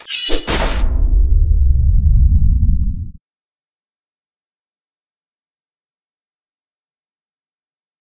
swingsword.ogg